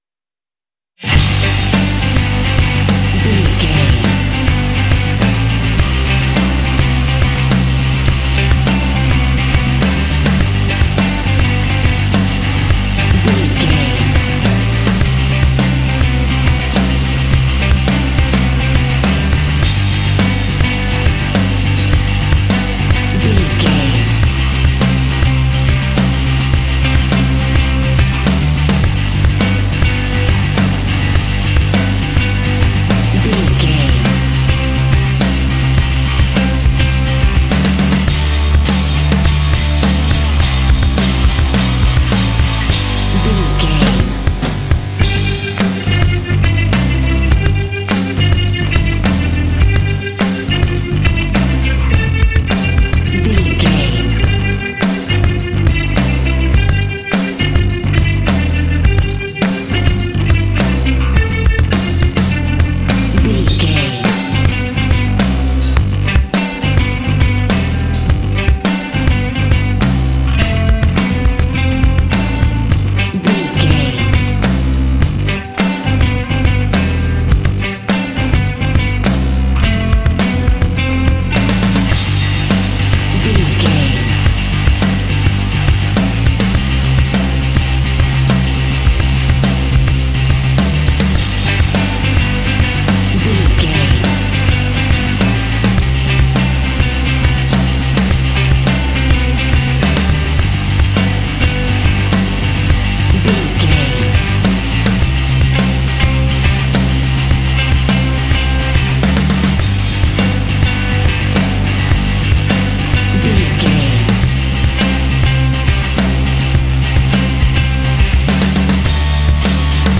This uplifting track is great for sports games.
Ionian/Major
driving
energetic
joyful
drums
bass guitar
electric guitar
progressive rock
alternative rock